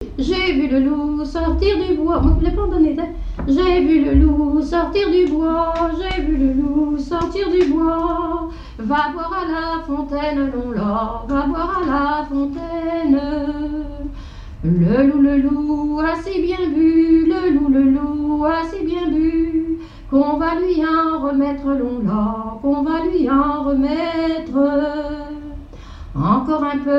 airs de danses et chansons traditionnelles
Pièce musicale inédite